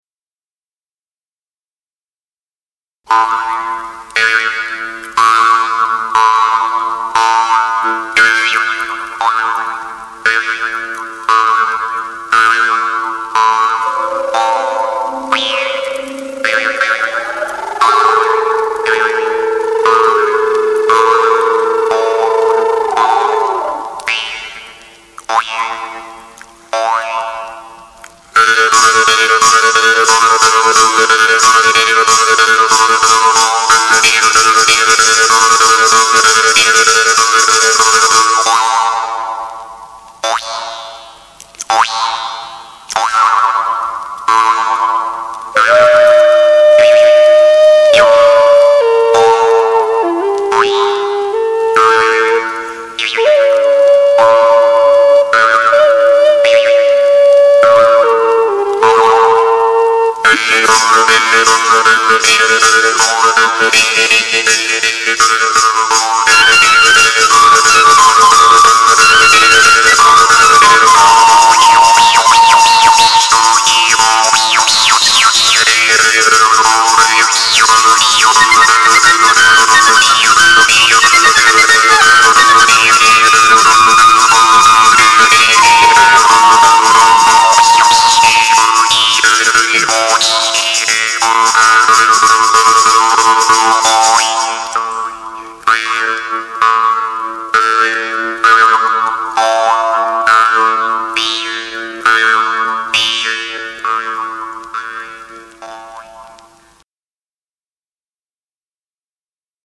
(SCACCIAPENSIERI - JEW' S HARP)